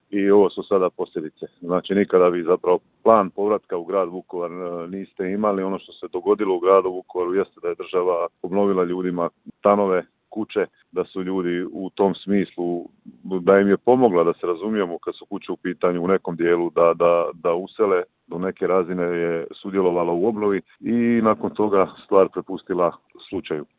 ZAGREB - Uoči obilježavanja vukovarske tragedije kratko smo razgovarali s gradonačelnikom Vukovara Ivanom Penavom.